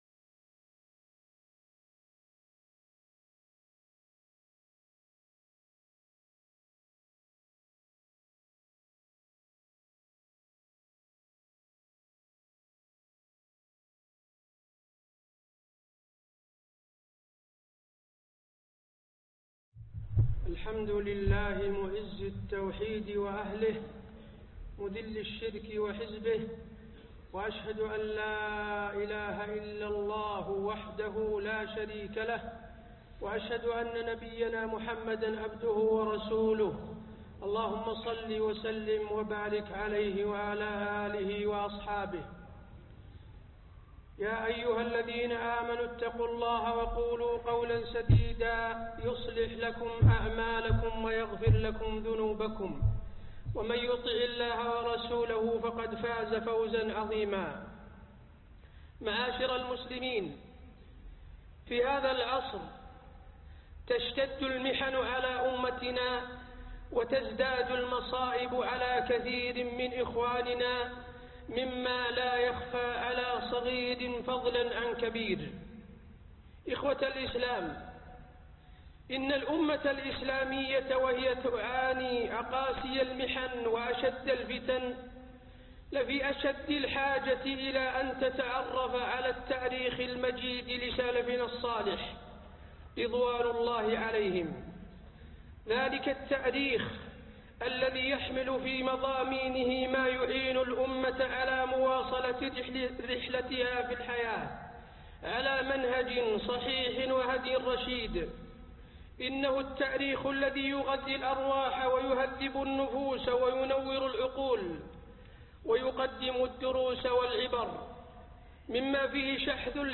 طريق الصحابة (خطب الجمعة من المسجد النبوي الشريف) - الشيخ حسين بن عبد العزيز آل الشيخ